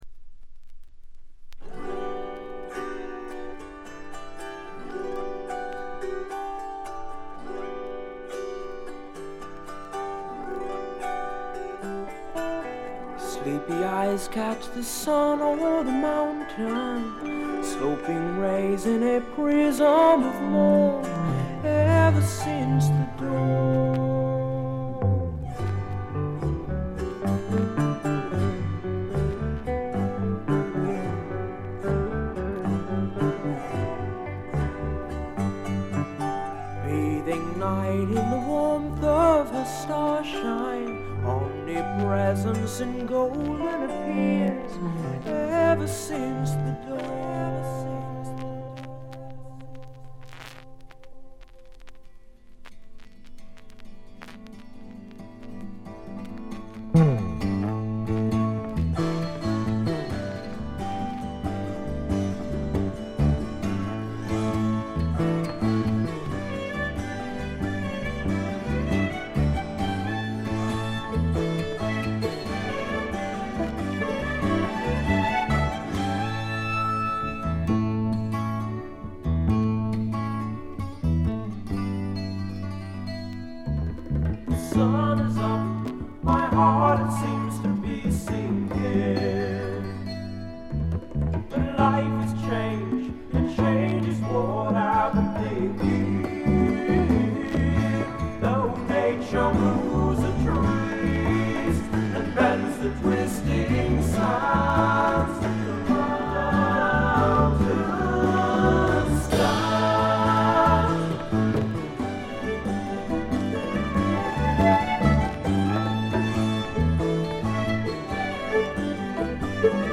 米国産クラシカル・プログレッシブ・フォークの名作です。
ギター、キーボード、ベースの他にヴァイオリン、ヴィオラ、チェロの専門奏者を擁したのが一番の特徴です。
かすかに漂うサイケ／アシッドな香りも人気の秘密でしょう。
試聴曲は現品からの取り込み音源です。